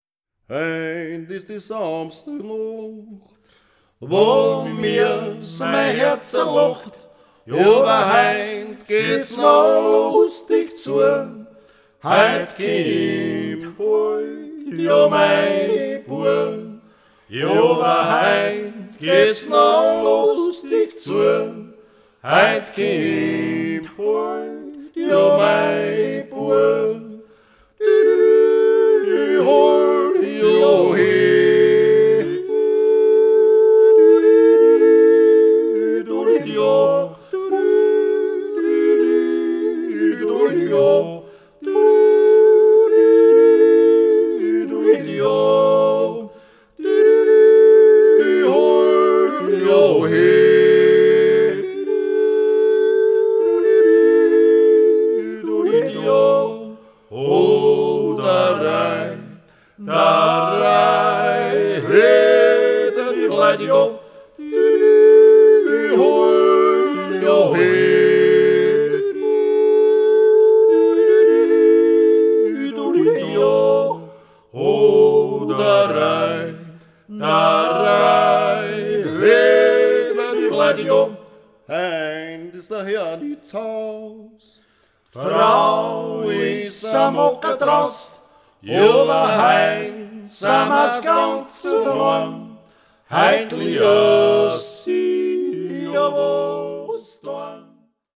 Multipart Music, Instrumentation of Sound, Instrumentalization of Sound, Sound and Society, Performance as Instrumentation, Tradition, Revival
Folk & traditional music